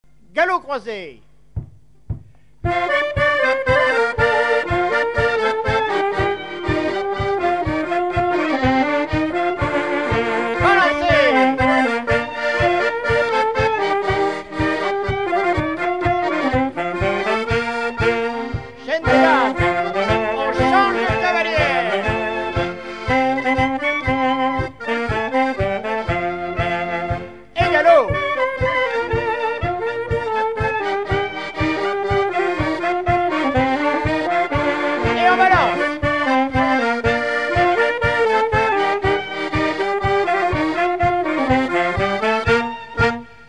Chaillé-sous-les-Ormeaux
Résumé instrumental
gestuel : danse
Pièce musicale inédite